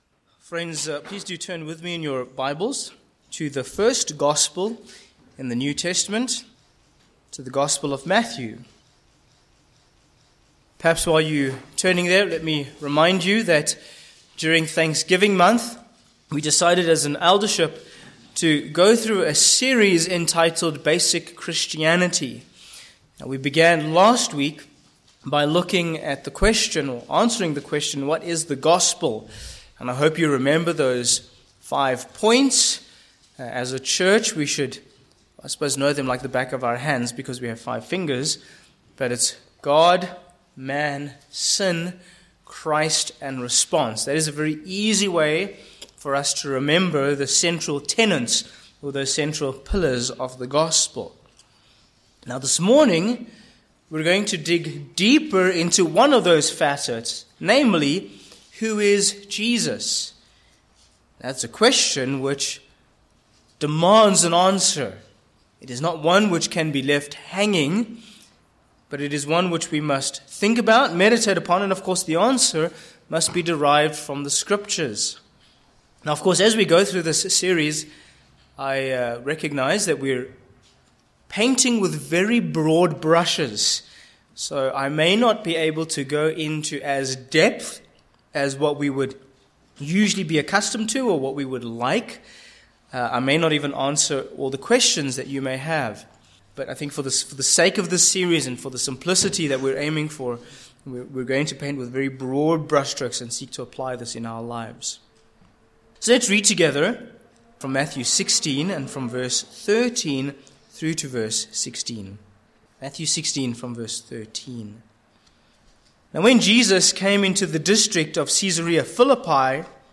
Matthew 16:13-16 Service Type: Morning Passage